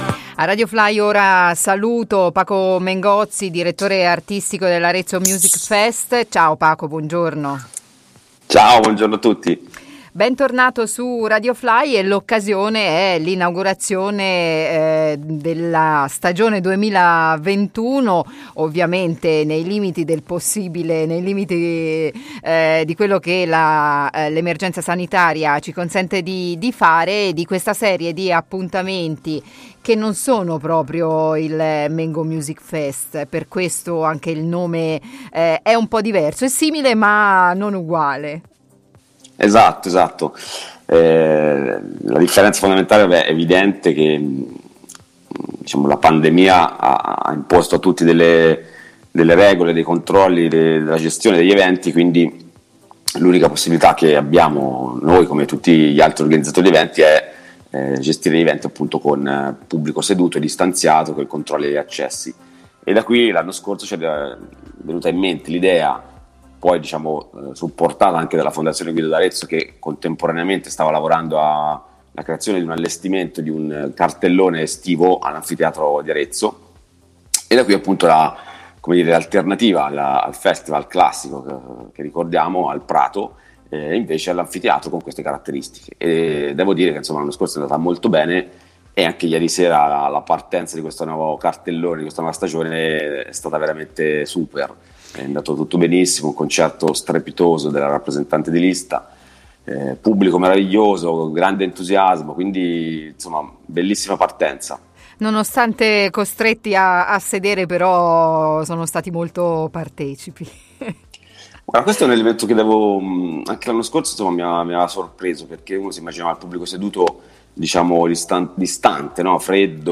Arezzo Music Fest: intervista